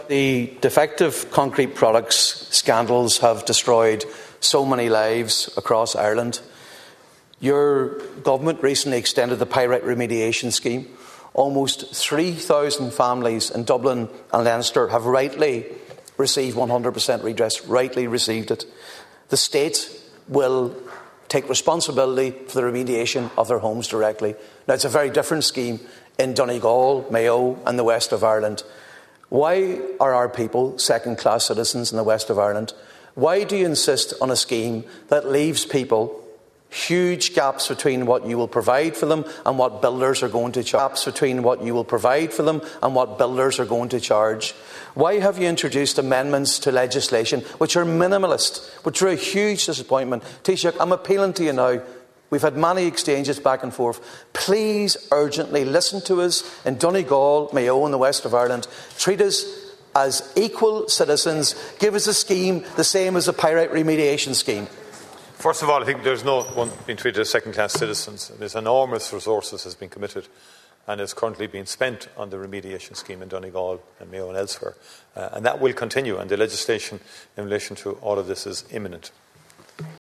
Deputy MacLochainn questioned the Taoiseach in the Dáil Chamber as to why the same has not been done for the Defective Concrete Block Grant Scheme: